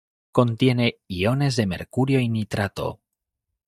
mer‧cu‧rio
/meɾˈkuɾjo/